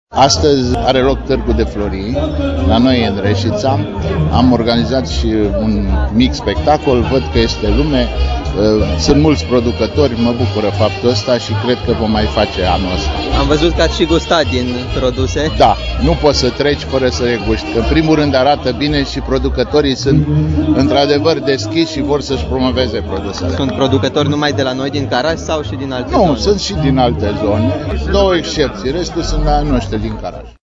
Printre cei care au ales să viziteze astăzi Parcul Tricolorului s-a numărat şi viceprimarul Reşiţei, Ioan Crina: